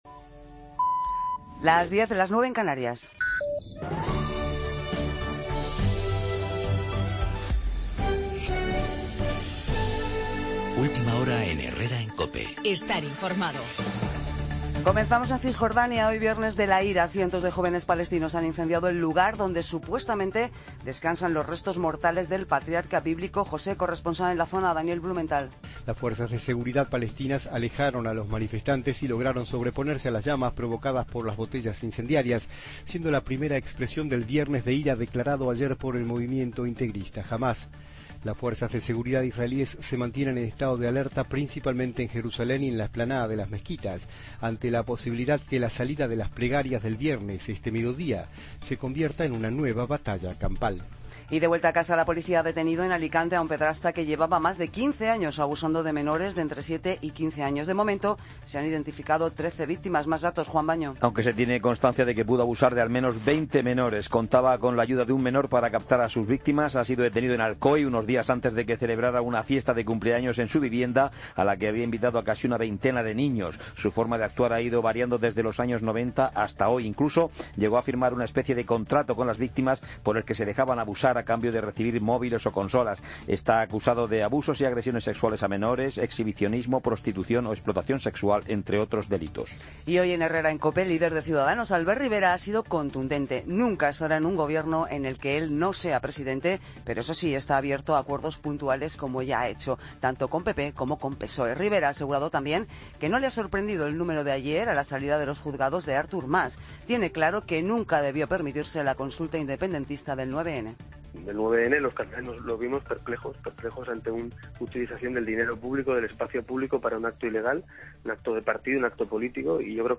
Noticias de las 10.00 horas, viernes 16 de octubre de 2015